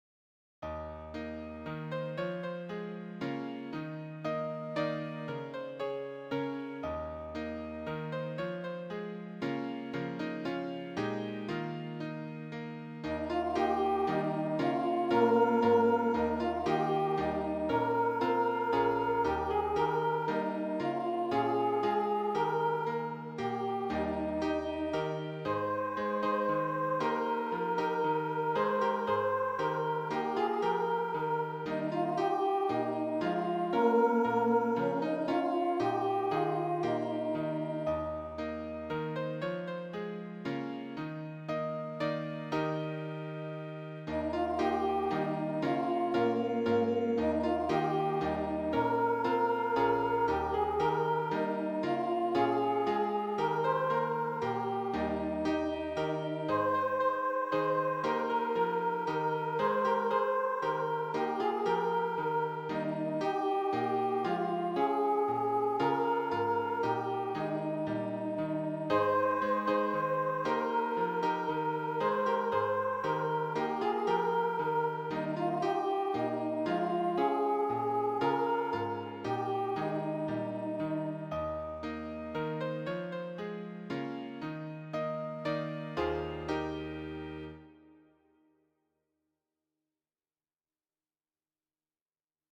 There are also demo MP3 files of the three songs.